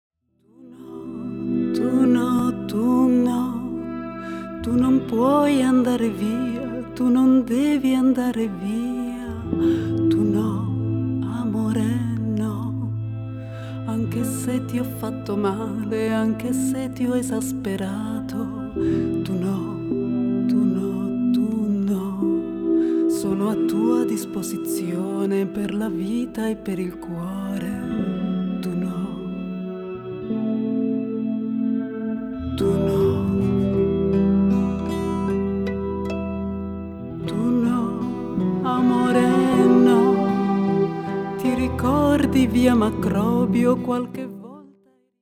voce solista